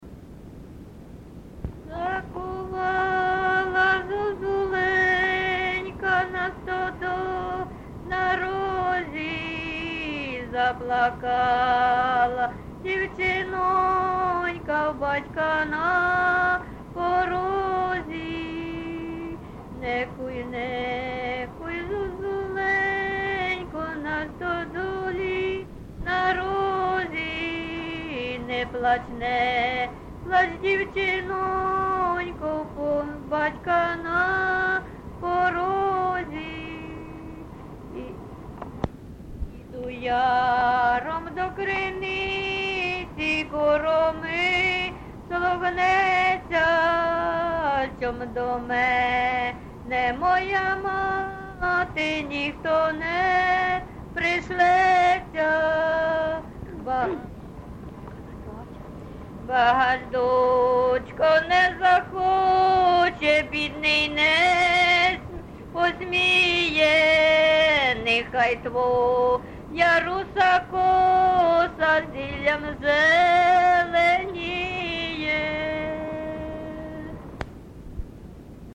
ЖанрПісні з особистого та родинного життя
МотивНещаслива доля, Журба, туга
Місце записус. Харківці, Миргородський (Лохвицький) район, Полтавська обл., Україна, Полтавщина